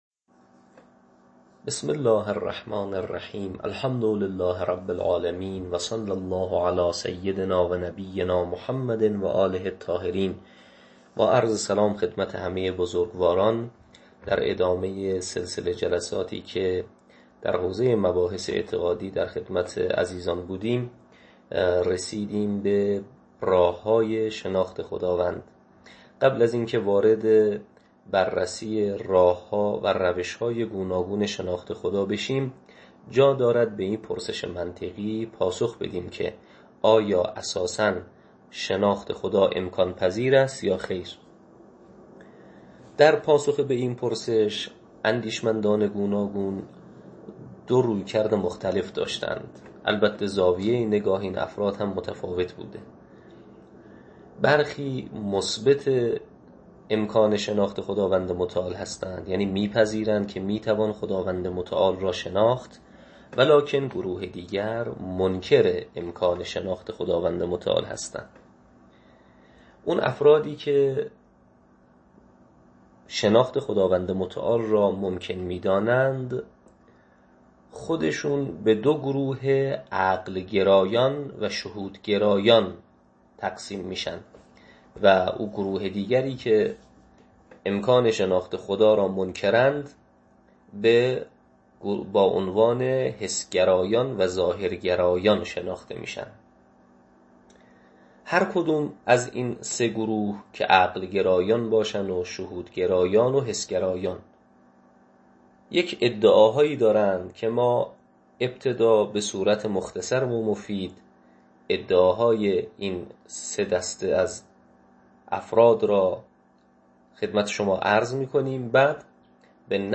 تدریس عقاید استدلالی یک